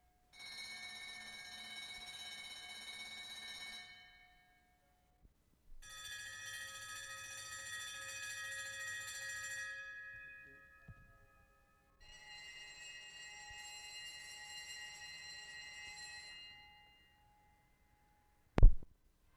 London, England June 4/75
A 1-6. LONDON EMERGENCY WARNING SIGNALS
Bedford/Siemens electric ambulance bell, older Siemens ambulance bell, old Windworth ambulance bell.